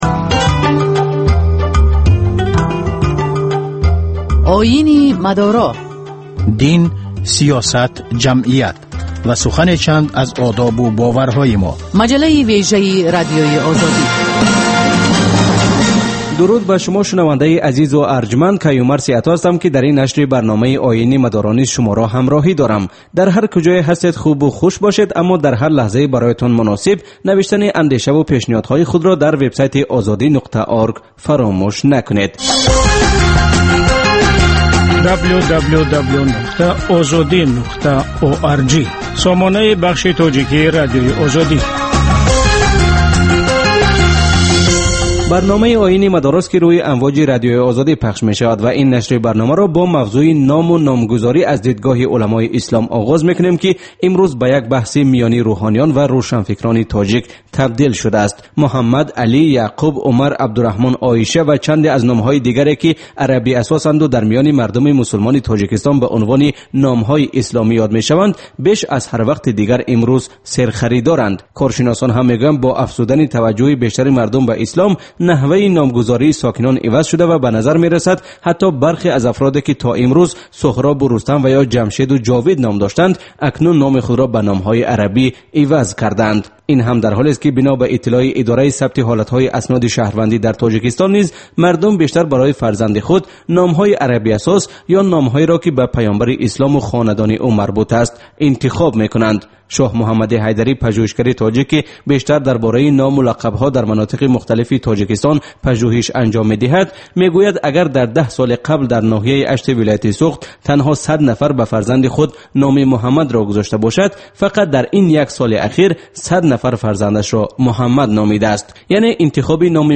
Гузориш, мусоҳиба, сӯҳбатҳои мизи гирд дар бораи муносибати давлат ва дин.